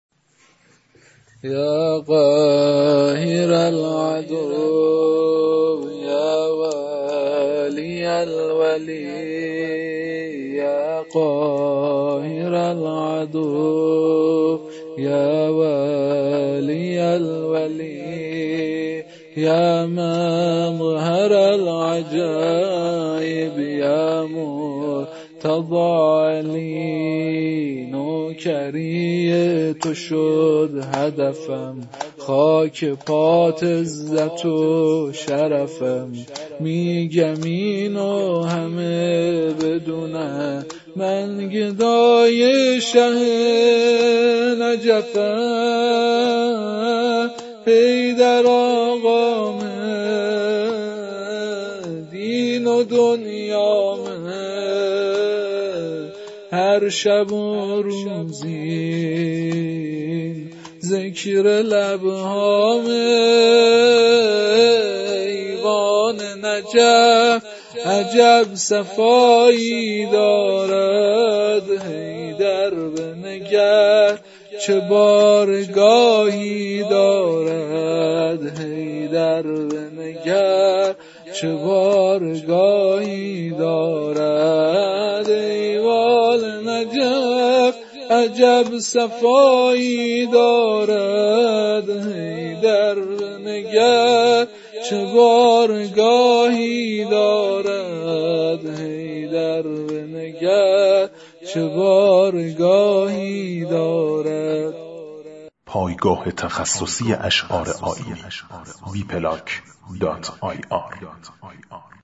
شور ، زمینه - - -